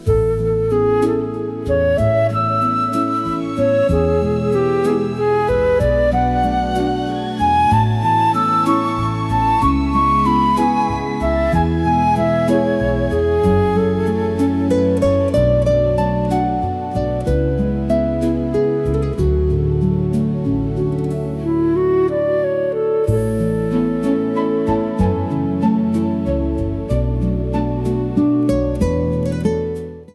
(मोहम्मद रफी स्टाइल - रोमांटिक धुन)